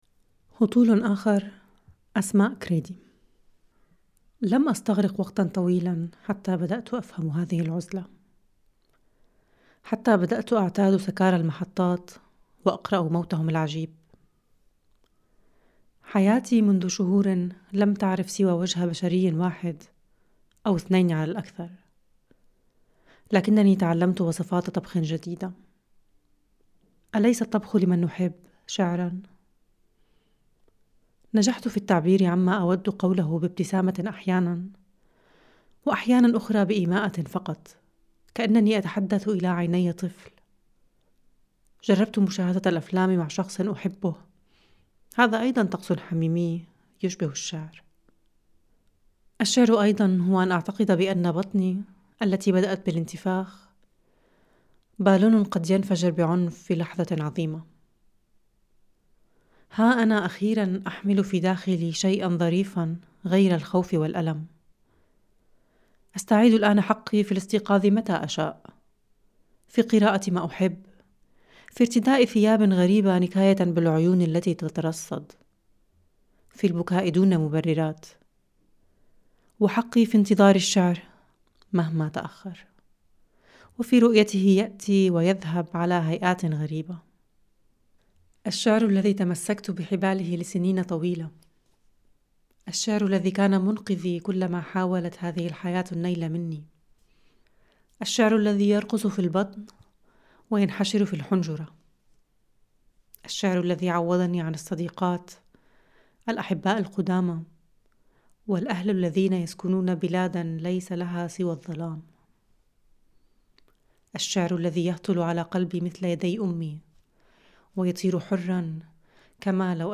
استماع إلى هذا النص، بصوت